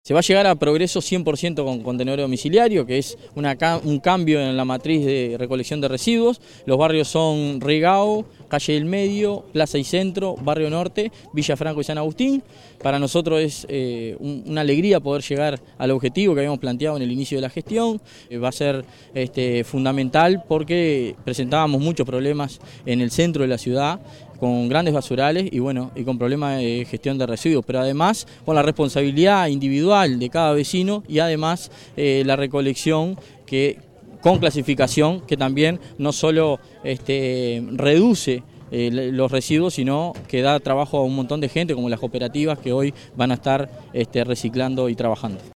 Estuvieron presentes en la entrega el Secretario General de la Intendencia de Canelones, Dr. Esc Francisco Legnani, el Director General de Gestión Ambiental, Leonardo Herou, el Alcalde del Municipio de Progreso, Claudio Duarte, vecinas y vecinos de la zona.